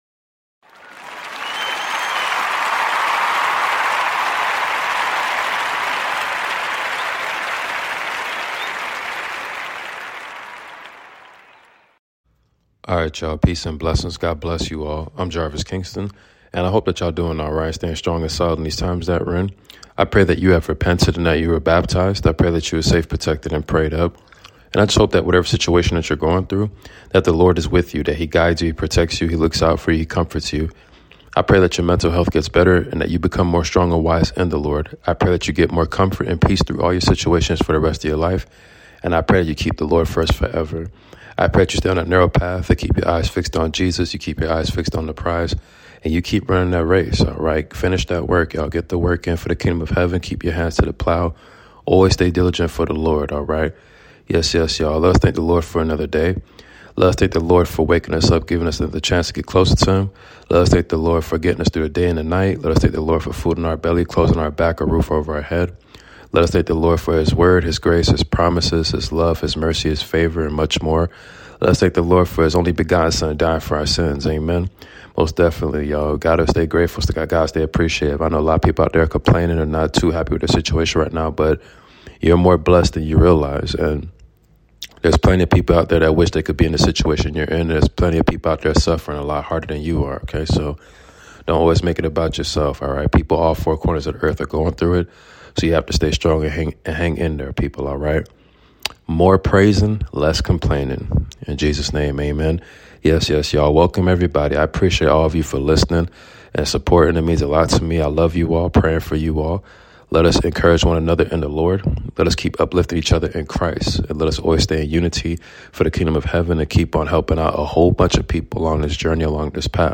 The Book Of 1 Esdras Chapters 1-4 Reading